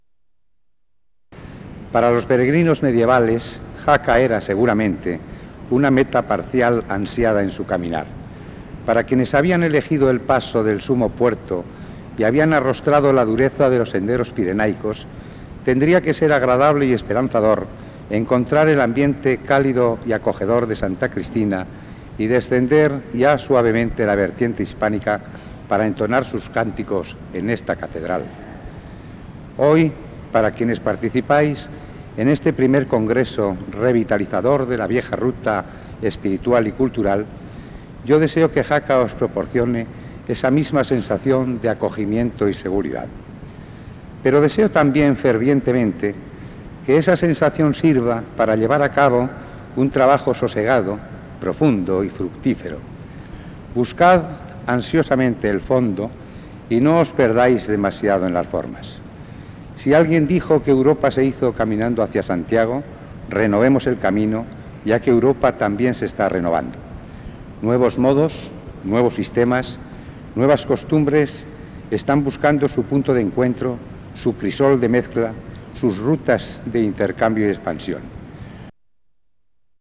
Bienvenida del Ilmo. Sr. D. Armando Abadía Urieta, alcalde de Jaca
I Congreso Internacional de Jaca. Saludo de las Autoridades. Miércoles 23 de septiembre, S.I. Catedral de Jaca, 1987